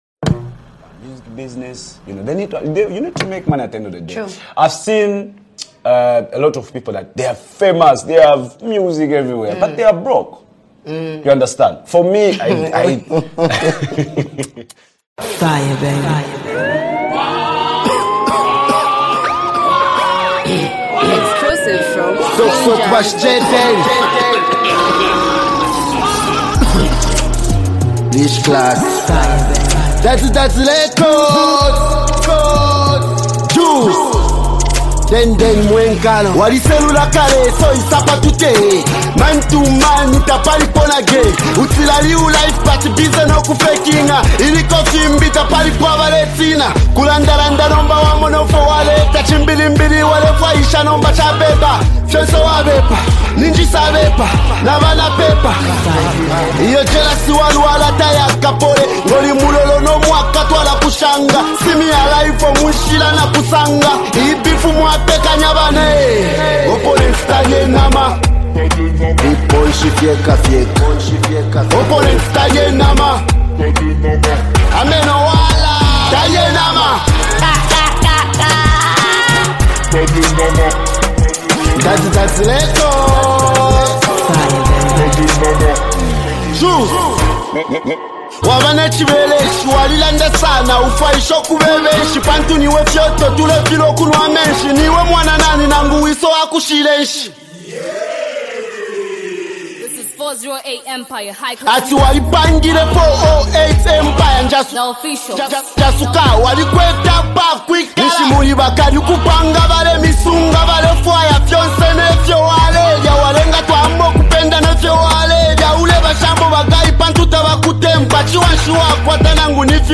hard-hitting diss track